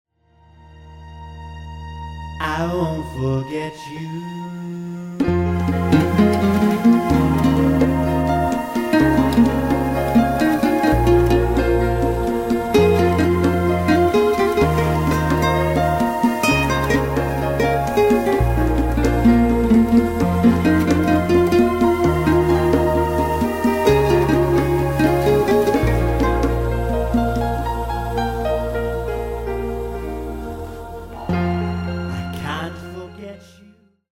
Piano/Keyboards & Lead Vocals